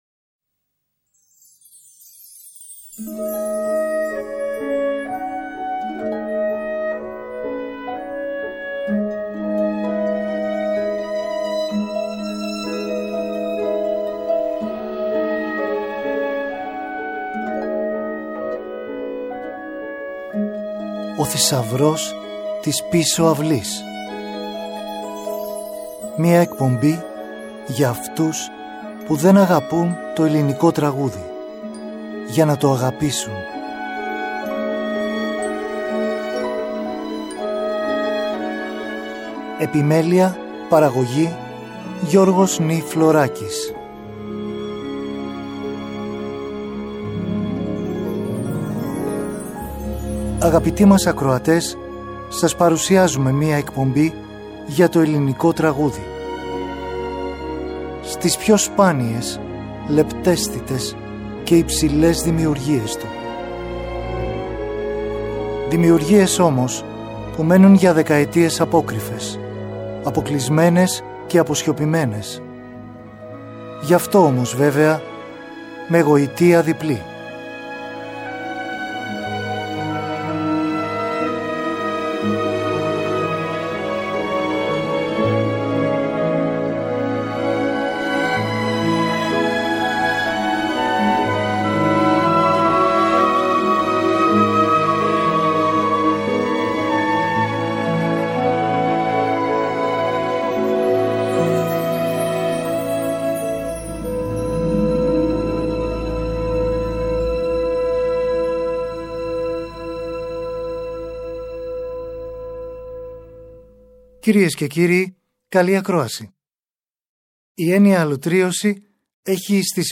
Μελοποιημένη Ποίηση Κ. Δημουλά – Μ. Πολυδούρη – Μ. Σαχτούρη – Ε. Κυρίτση